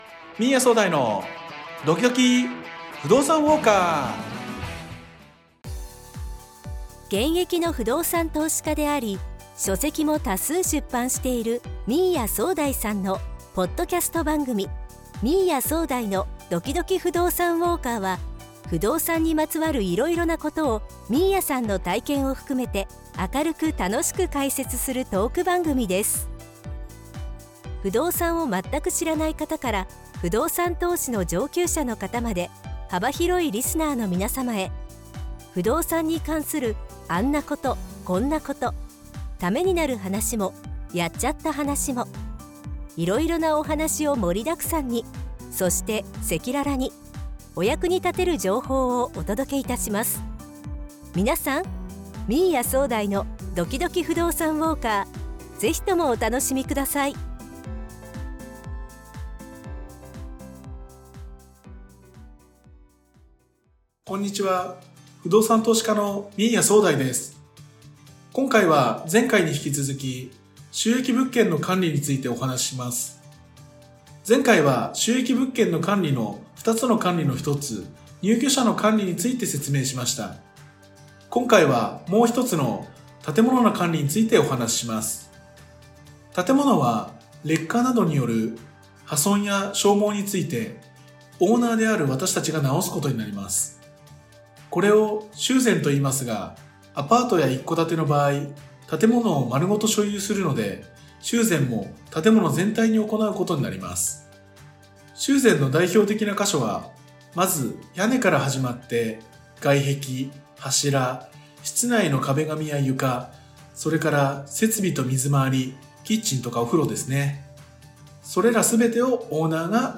明るく楽しく解説するトーク番組です。